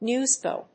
アクセント・音節néws・bòy